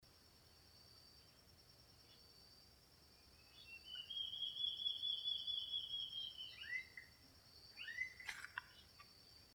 Borralhara-assobiadora (Mackenziaena leachii)
050414-batara-pintado.MP3
Nome em Inglês: Large-tailed Antshrike
Localidade ou área protegida: Reserva Privada y Ecolodge Surucuá
Condição: Selvagem
Certeza: Gravado Vocal